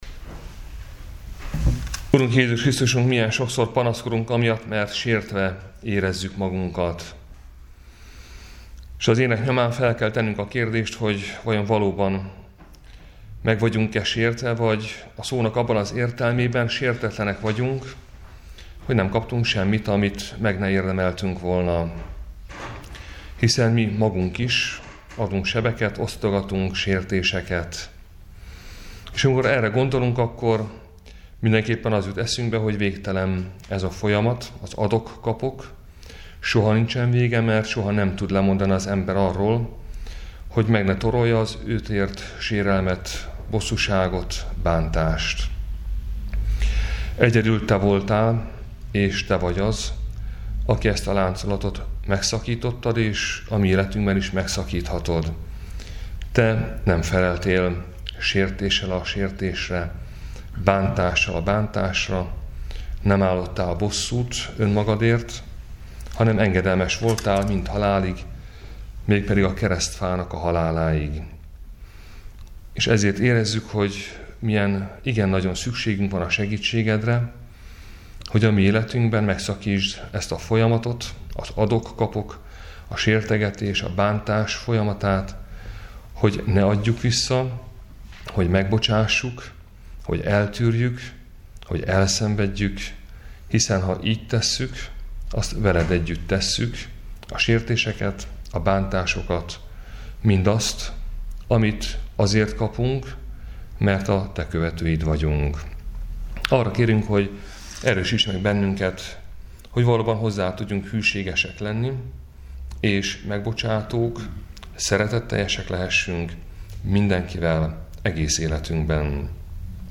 Áhítat, 2019. április 3.